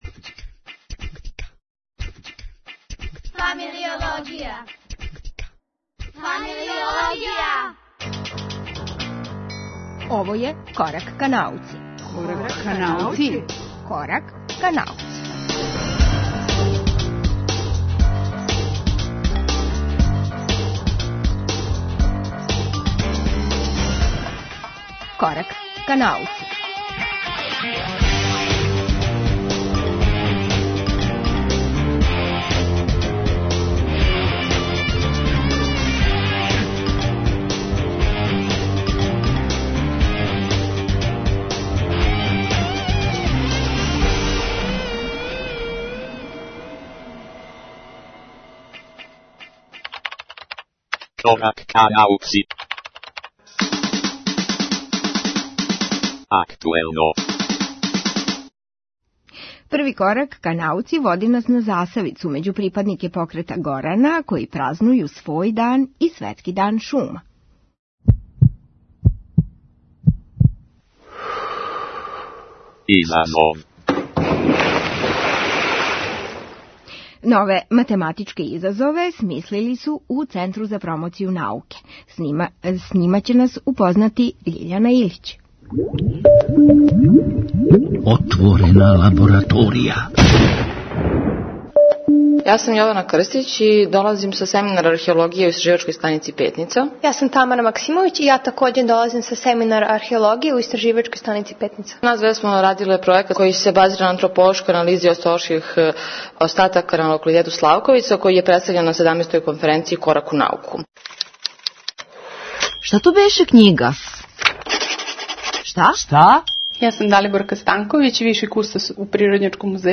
Путопис (древни рудари прибојског краја)